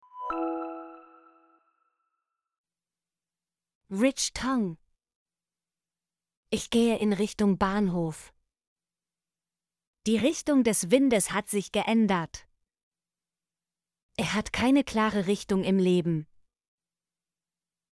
richtung - Example Sentences & Pronunciation, German Frequency List